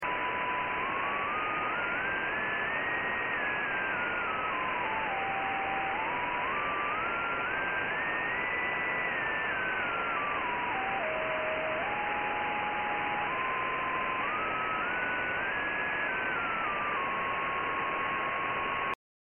По этой схеме шумы немного ниже. Это слышно при уровне с ГСС 0.4мкВ.
На скриншоте виден этот слабый сигнал.